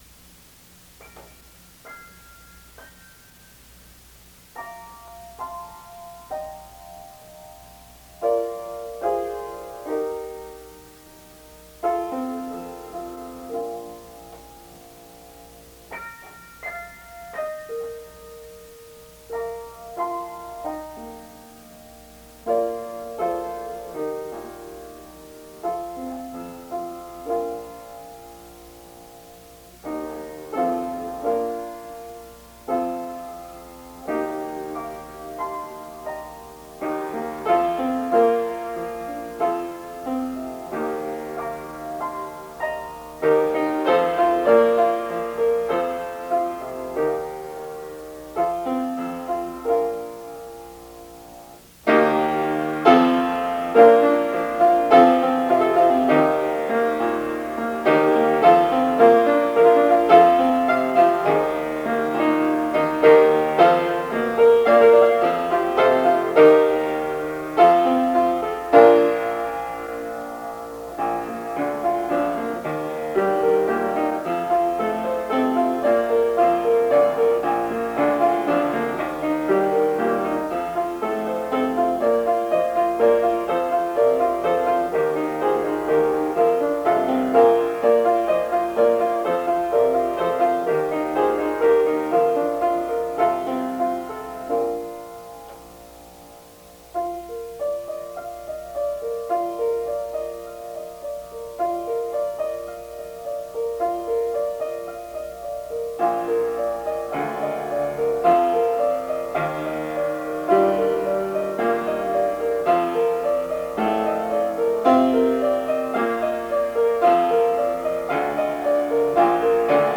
The following pieces consist of MIDI and/or MP3 files for the piano.
It was hard to get the midi to sound correct (I entered it by hand) but my live (MP3) recordings have mistakes - so you have a choice of accurate and uninspired, or inaccurate but at least not flat.
I experimented with polymetry in this piece.